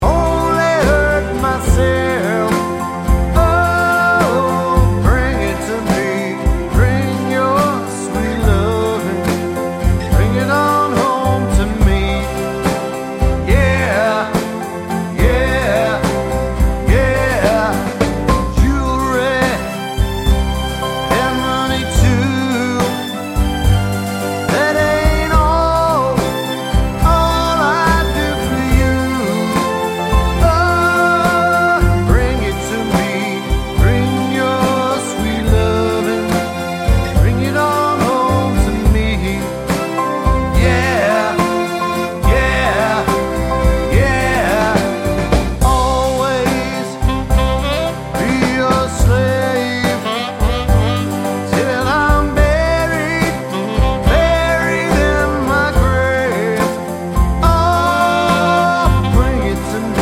no Backing Vocals Soul / Motown 2:46 Buy £1.50